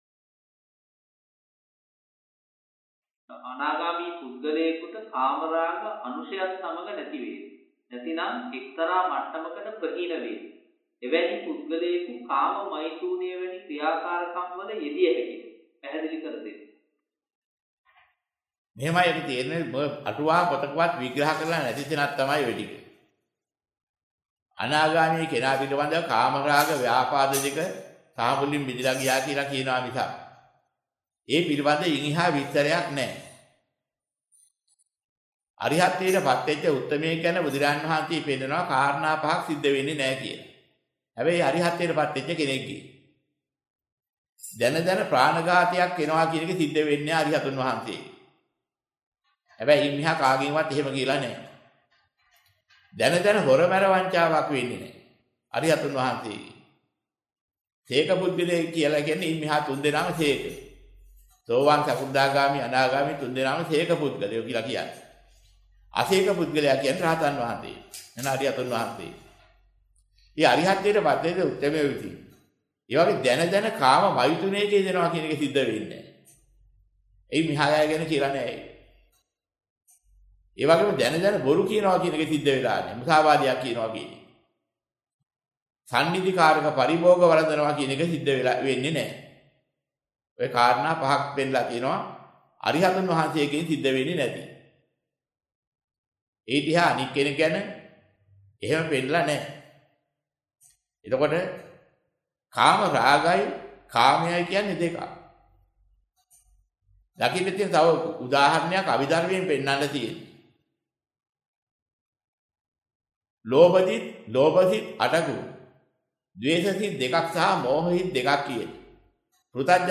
වෙනත් බ්‍රව්සරයක් භාවිතා කරන්නැයි යෝජනා කර සිටිමු 08:48 10 fast_rewind 10 fast_forward share බෙදාගන්න මෙම දේශනය පසුව සවන් දීමට අවැසි නම් මෙතැනින් බාගත කරන්න  (5 MB)